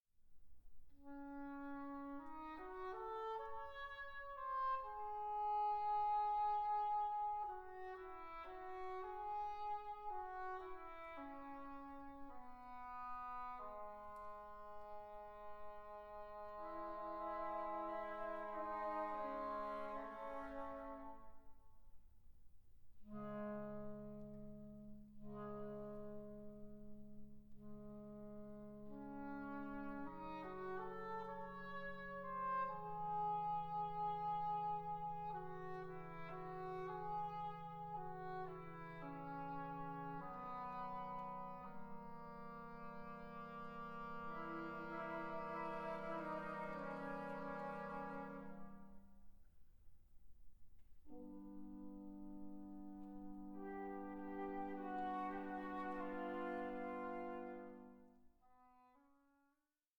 ambitious studio recording